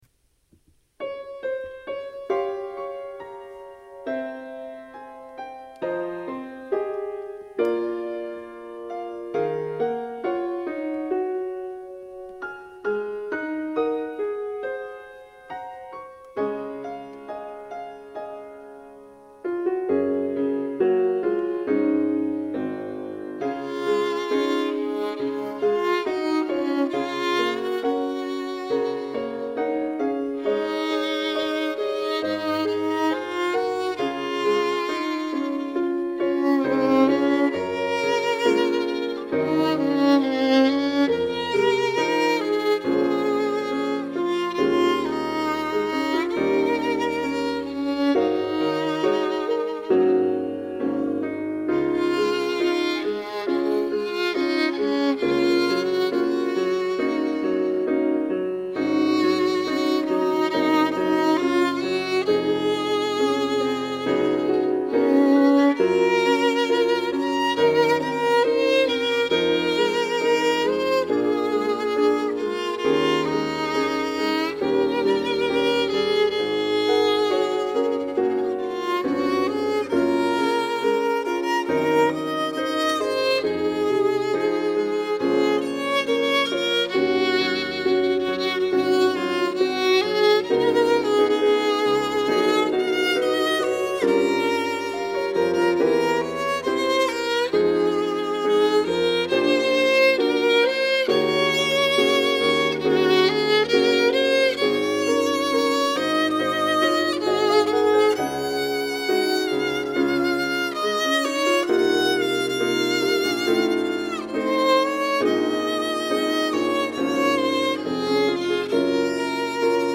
특송과 특주 - 믿음의 고백